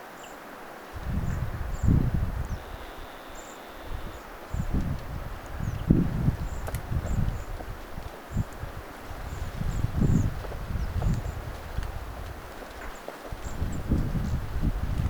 tuollaisia tilhien ääniä
tuollaisia_tilhien_aania.mp3